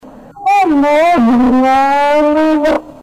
E la tristezza che lega le sue parole è davvero grande, e lei non riesce a nasconderla nemmeno dietro il tono "ruffiano" che usa per comunicare con noi.